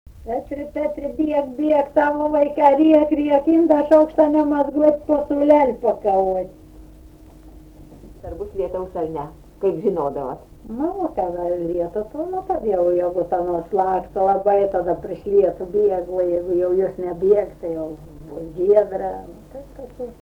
smulkieji žanrai
vokalinis